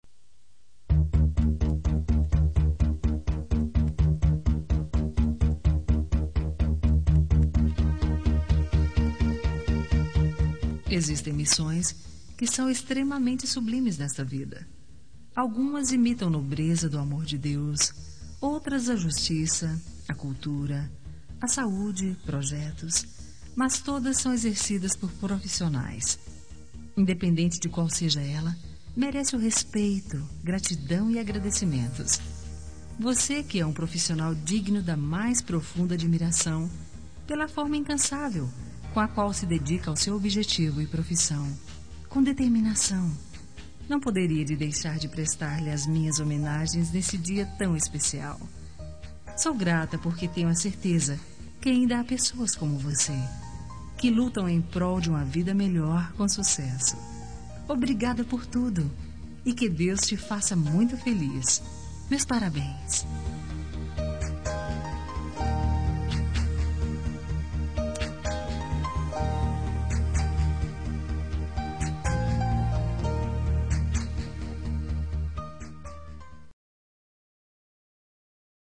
Homenagem ao Profissional – Voz Feminina – Cód: 310
310-homenagem-profissional-fem-1.m4a